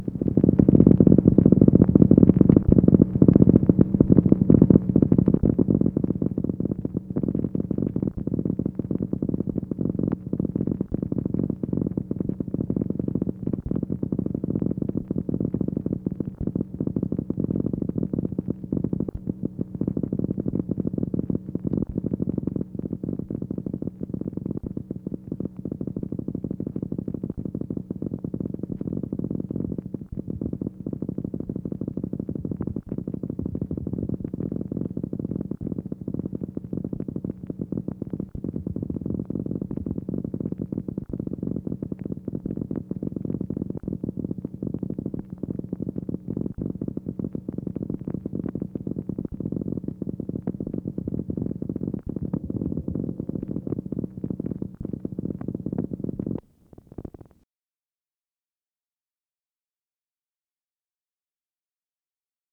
MACHINE NOISE, January 17, 1964
Secret White House Tapes | Lyndon B. Johnson Presidency